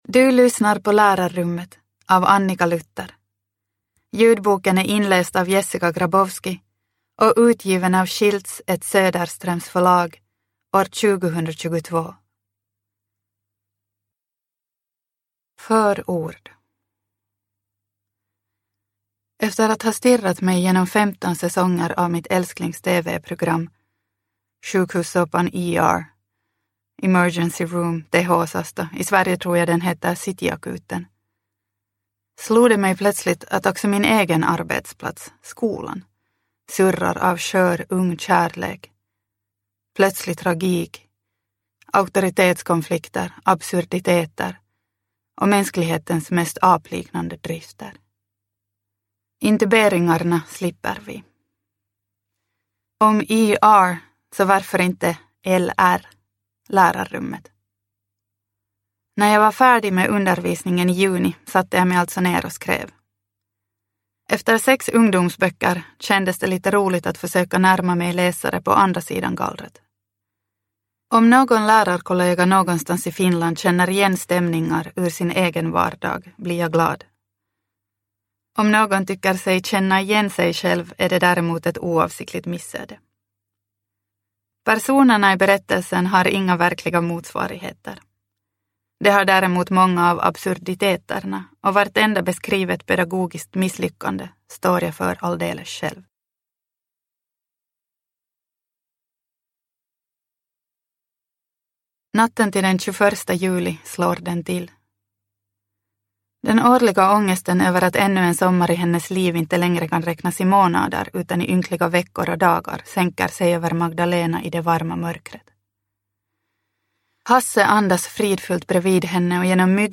Lärarrummet – Ljudbok – Laddas ner